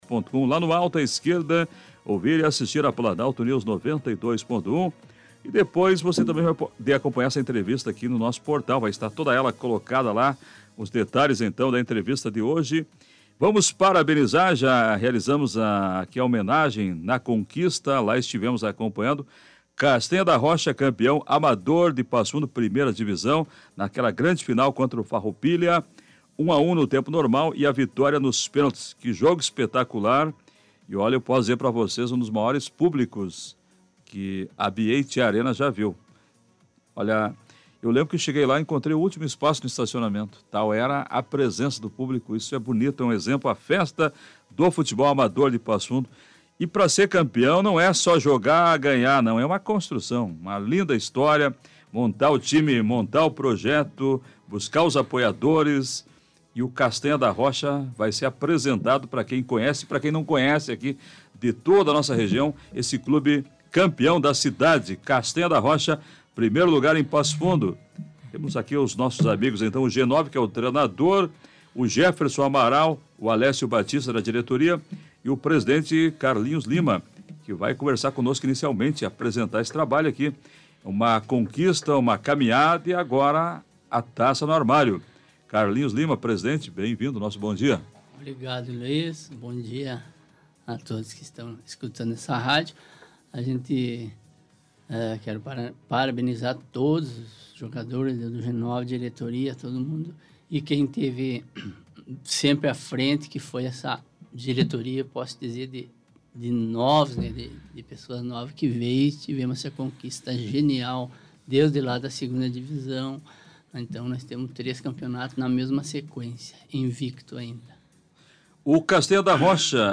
Os campeões estiveram no estúdio da Rádio Planalto News (92.1).